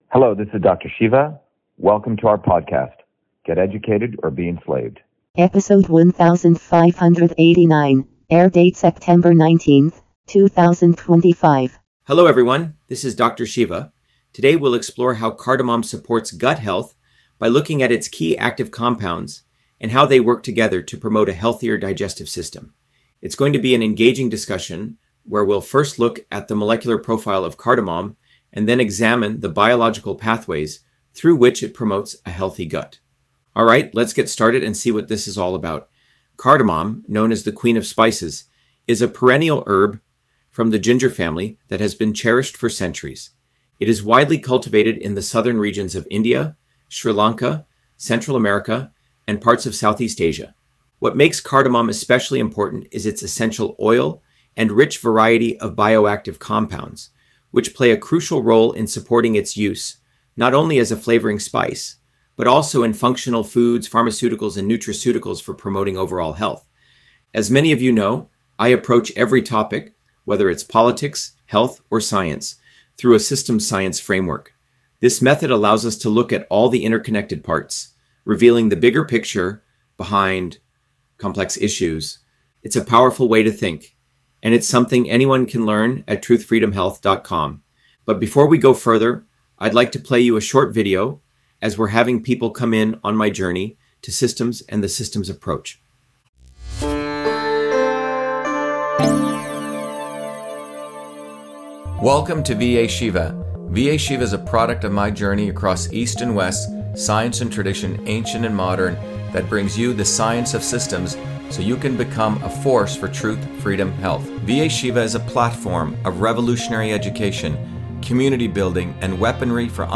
In this interview, Dr.SHIVA Ayyadurai, MIT PhD, Inventor of Email, Scientist, Engineer and Candidate for President, Talks about Cardamom on Gut Health: A Whole Systems Approach